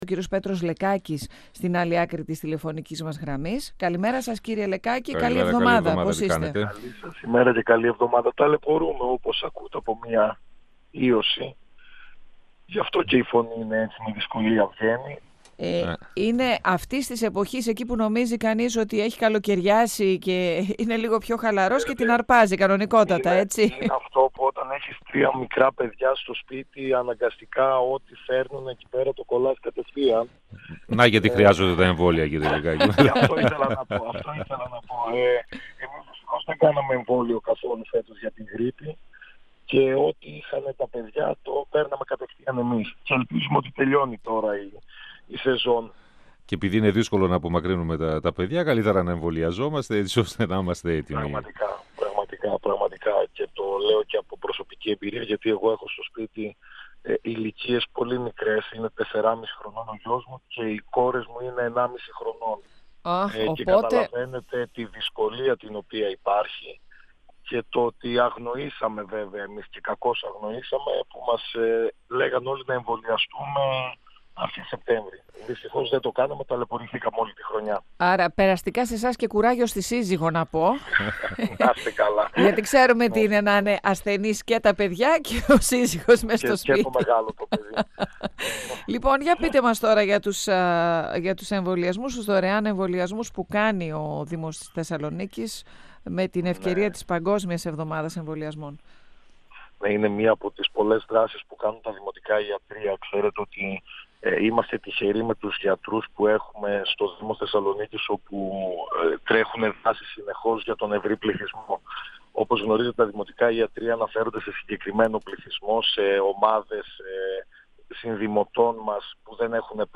O αντιδήμαρχος Κοινωνικής Πολιτικής και Αλληλεγγύης, Πέτρος Λεκάκης, στον 102FM του Ρ.Σ.Μ. της ΕΡΤ3
Συνέντευξη